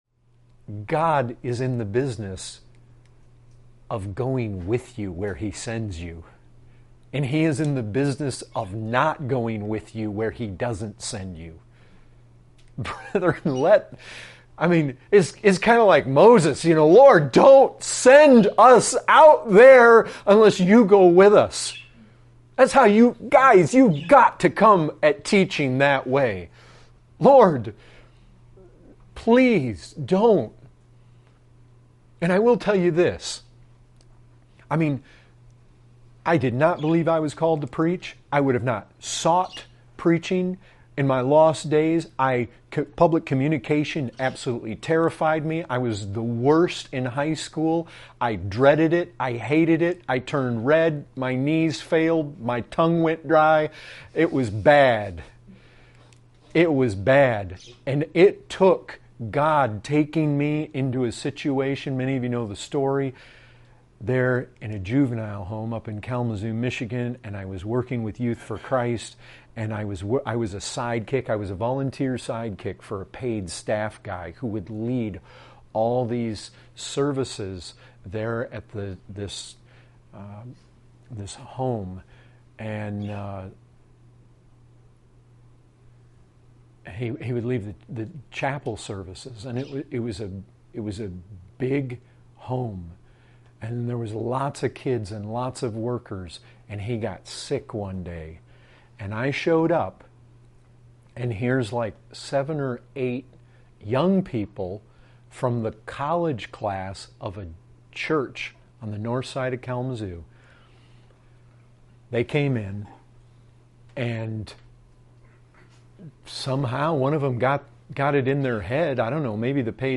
8:32 | Excerpt | One of the things that happened when I stood in that pulpit, that first time, is my legs barely carried me into the pulpit.